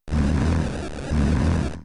Snore.mp3